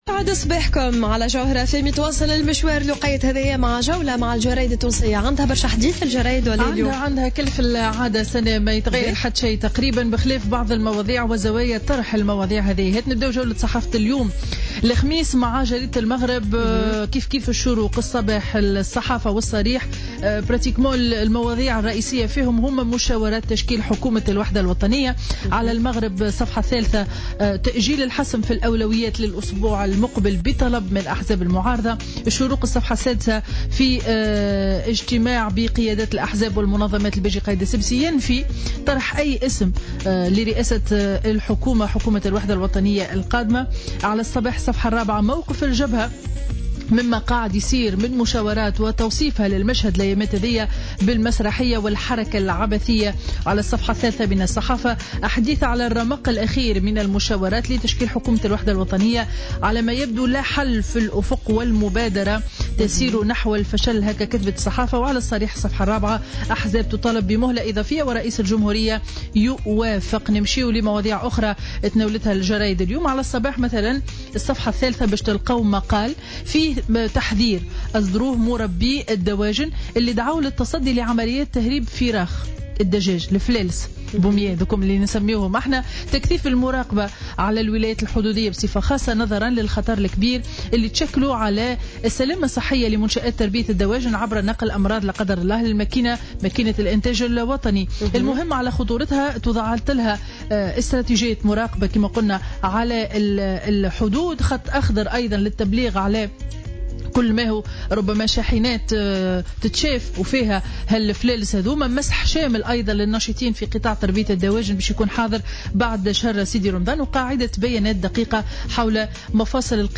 Revue de presse du jeudi 23 juin 2016